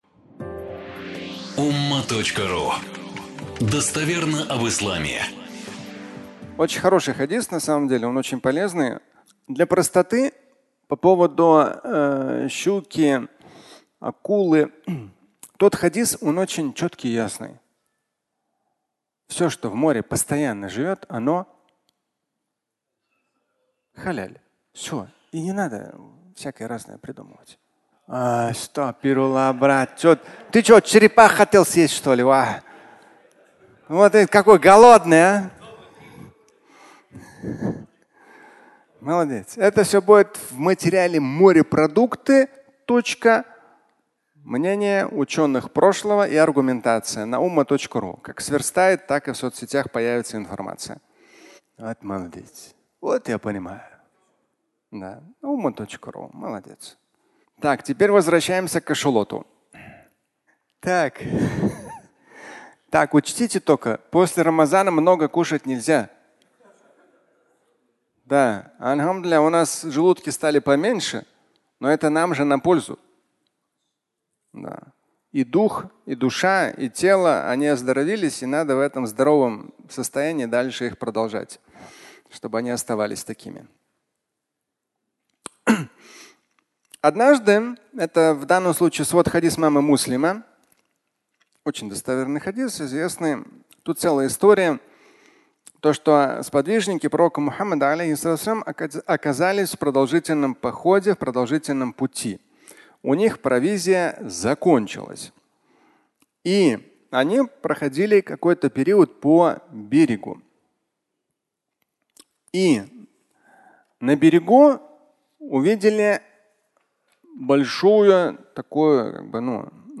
Кашалот (аудиолекция)
Пятничная проповедь